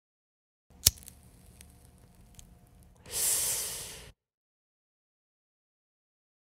Tiếng bật lửa Hút Thuốc Lá và hít nhẹ một hơn
Thể loại: Tiếng hoạt động con người
Description: Download âm thanh, tải hiệu ứng tiếng động tiếng bật lửa hút thuốc Lá và hít nhẹ một hơn dài và thở ra chân thực nhất miêu tả người đàn ông hút thuốc dùng làm phim, ghép video, edit, chỉnh sửa video không bản quyền.
tieng-bat-lua-hut-thuoc-la-va-hit-nhe-mot-hon-www_tiengdong_com.mp3